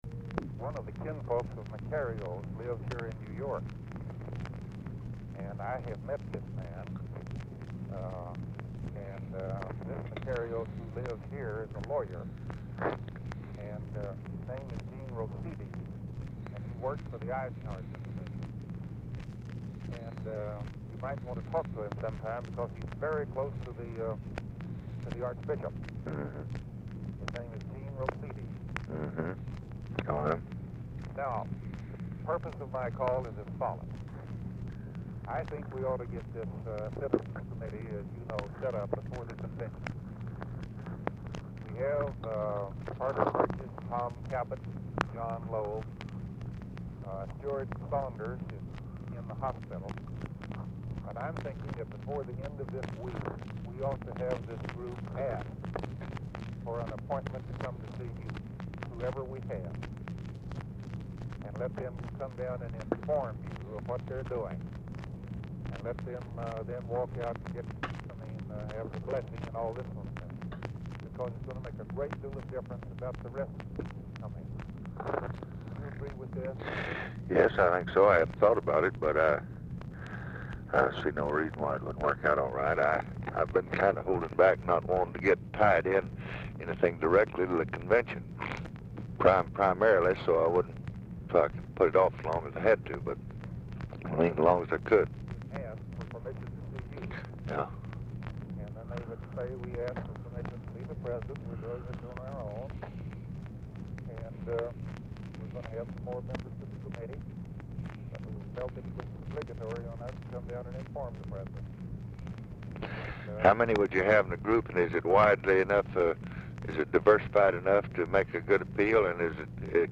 Telephone conversation # 4856, sound recording, LBJ and ROBERT ANDERSON, 8/10/1964, 10:00AM | Discover LBJ
Format Dictation belt
Location Of Speaker 1 Mansion, White House, Washington, DC